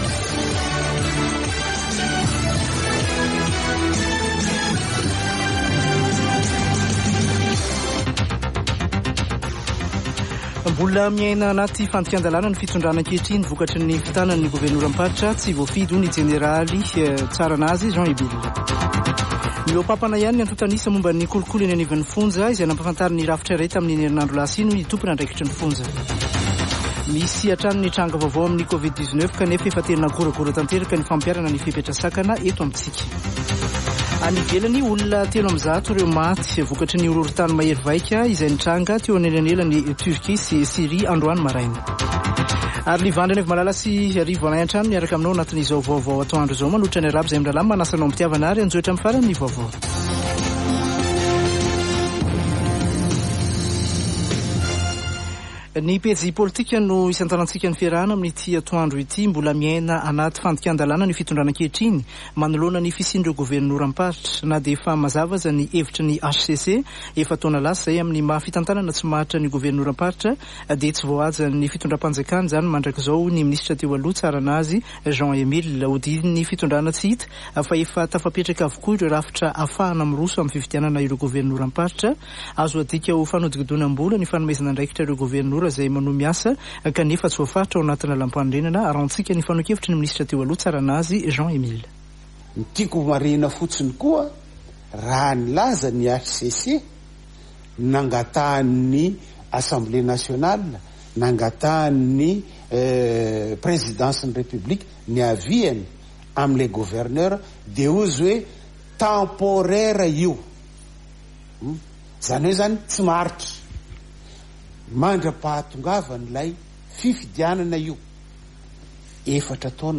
[Vaovao antoandro] Alatsinainy 6 febroary 2023